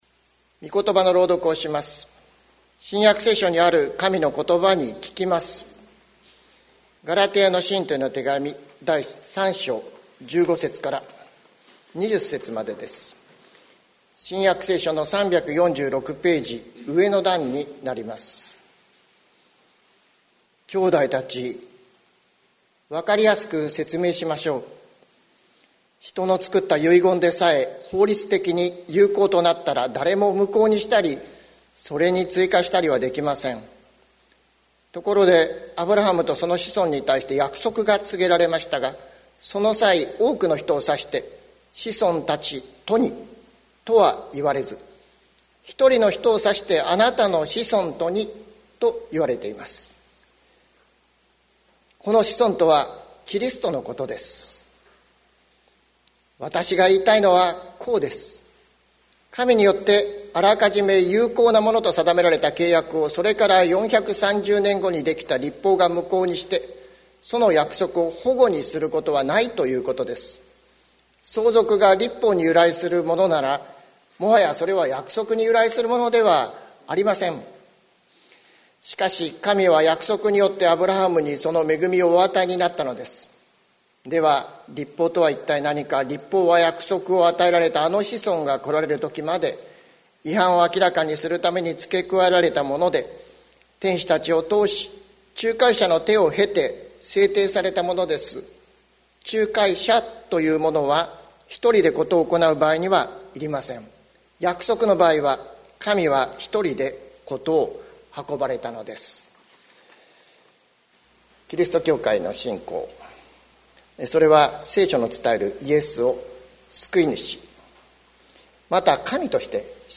2021年06月06日朝の礼拝「神さまの約束とは」関キリスト教会
関キリスト教会。説教アーカイブ。